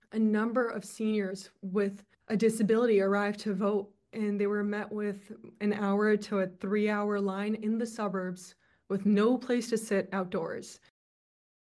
State Representative Nabeela Syed has introduced a measure that establishes curbside voting during early voting and on Election Day.  She says during the November Election, some voting lines were too long for some people.